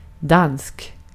Ääntäminen
IPA: /dansk/